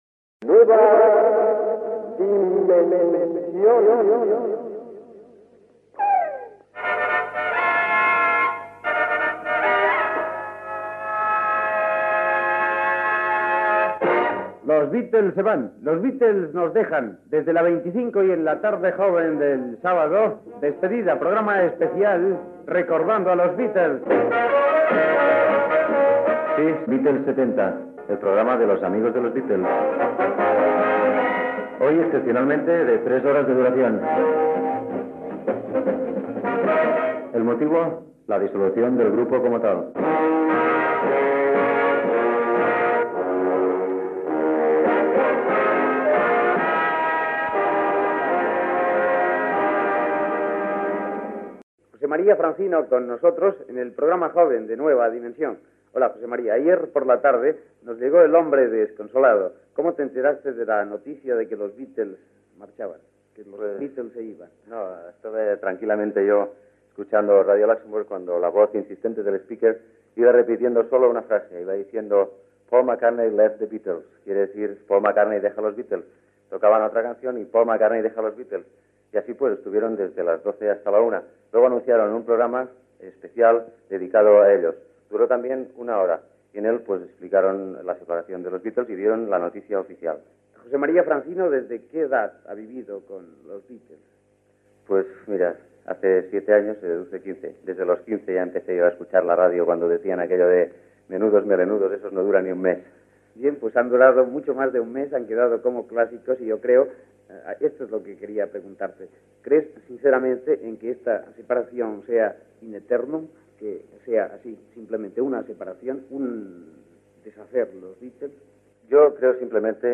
Musical
El programa es va realitzar, en directe, al dia següent del comunicat de dissolució del grup, a l'auditori de l'emissora. Es va repassar la trajectòria del grup de Liverpool dia a dia i les seves cançons. Hi va haver trucades telefòniques i un concurs de preguntes sobre el grup amb regal de discos.